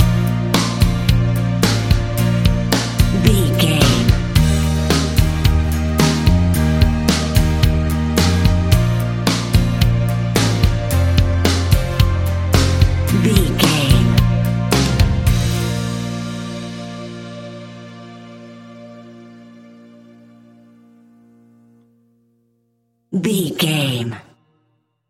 Aeolian/Minor
fun
energetic
uplifting
instrumentals
guitars
bass
drums
organ